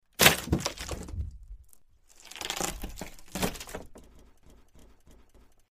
Tiếng Gỗ gãy, bẻ gỗ, gỗ bị nứt… Rắc, Rầm….
Thể loại: Tiếng động
Description: Tiếng gỗ gãy vang lên khô khốc, một tiếng rắc giòn tan, như sợi gân bị bẻ đứt. Ngay sau đó là âm rầm nặng nề khi những thanh gỗ vỡ vụn, nứt toác, tách rời và rụng xuống.
tieng-go-gay-be-go-go-bi-nut-rac-ram-www_tiengdong_com.mp3